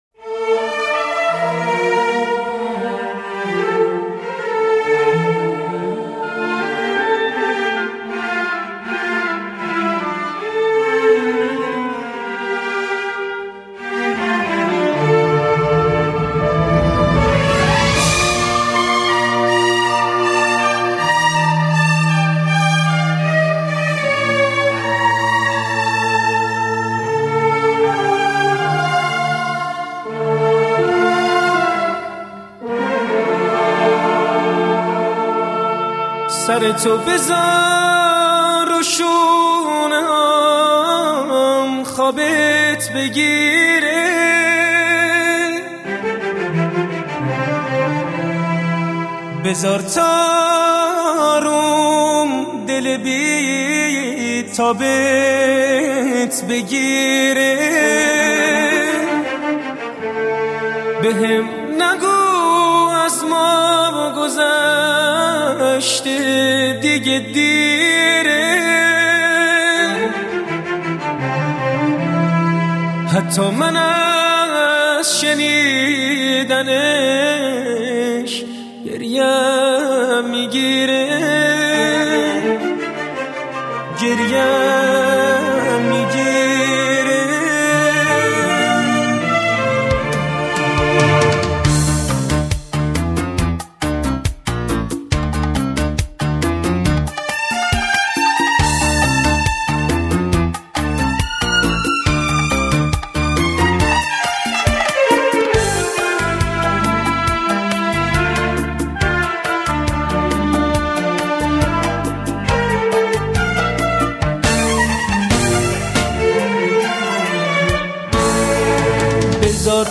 آرام‌بخش و عاشقانه